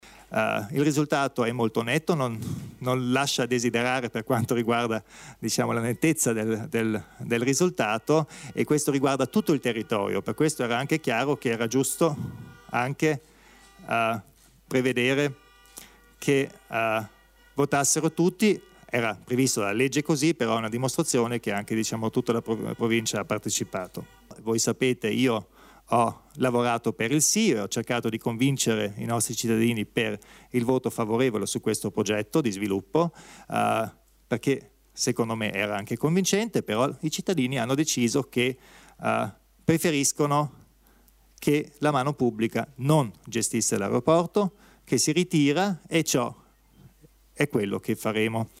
Il Presidente Kompatscher illustra i prossimi passi per il futuro dell'aeroporto di Bolzano